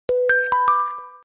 Звуки телефона Xiaomi
• Качество: высокое